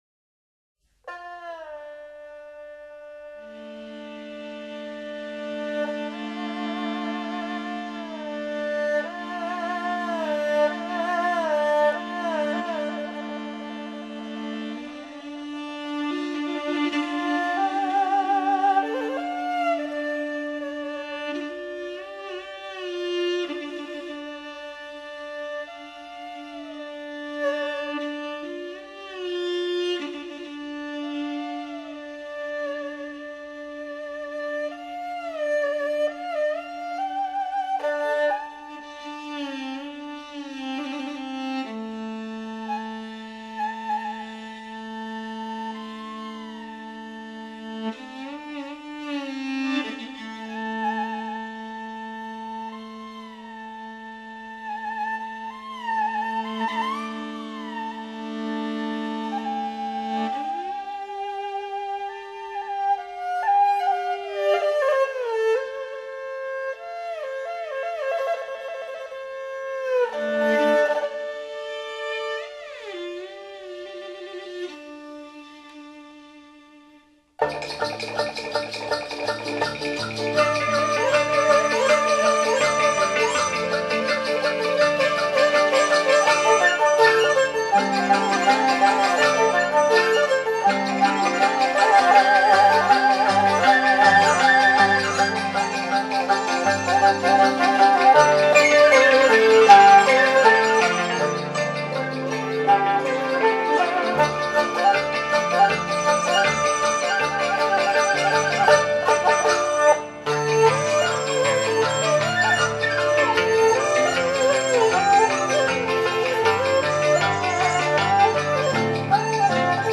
她的演奏音色纯美，韵味浓郁，技巧娴熟，在左手揉弦、滑音以及右手弓法，音色变化细腻和幅度上均有其独到之处。